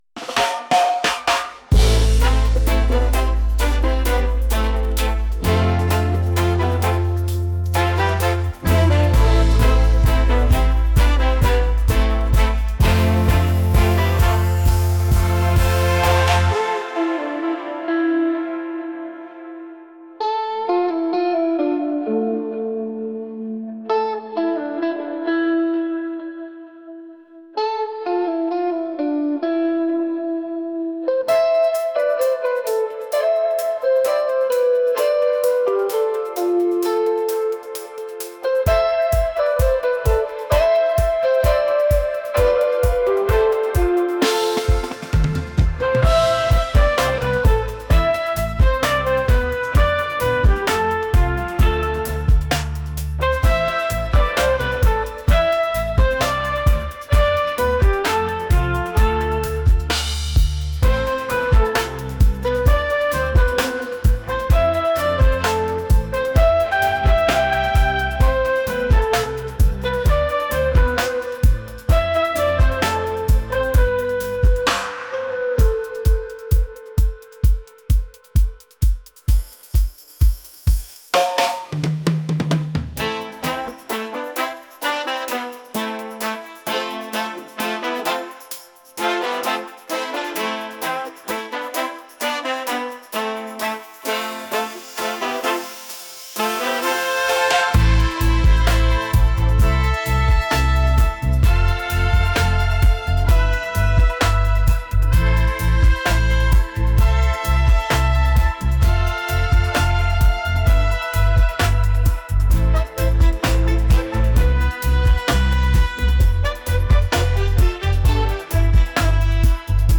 soul | reggae